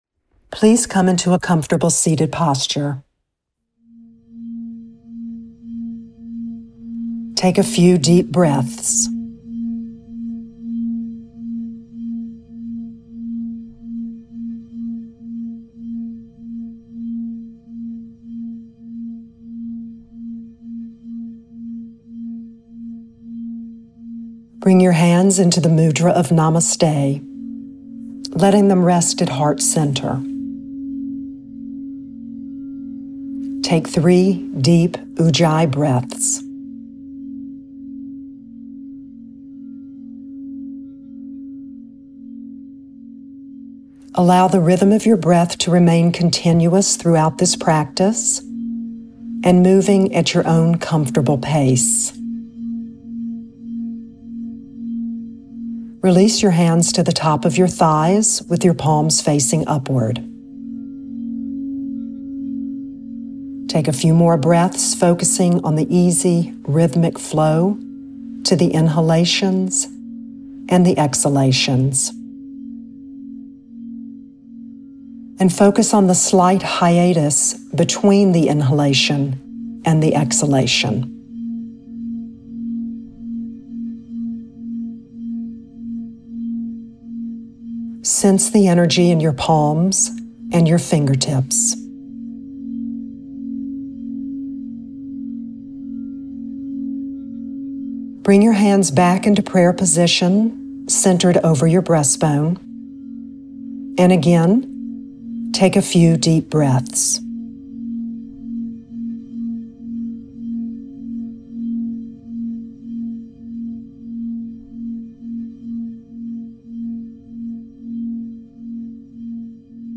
Gratitude Mudra Meditation